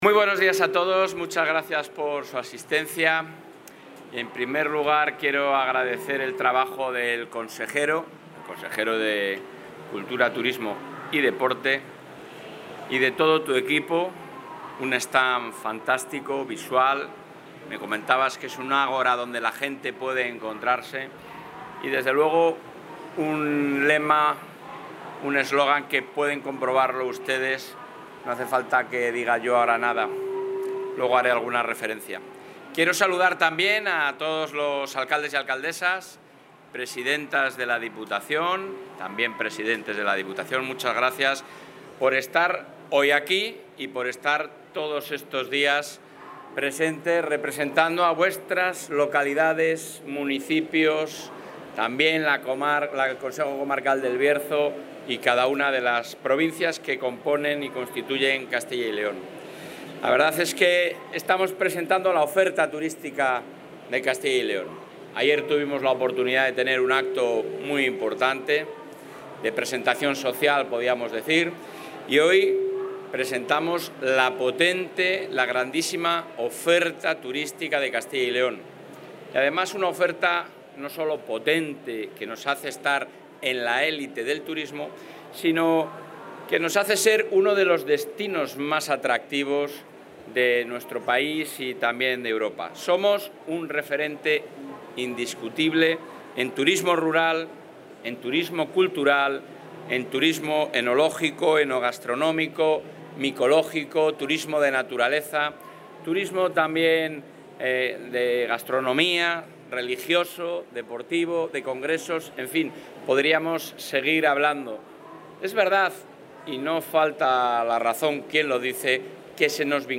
El presidente del Gobierno autonómico, Alfonso Fernández Mañueco, ha visitado hoy la 44 edición de la Feria Internacional...
Intervención del presidente de la Junta.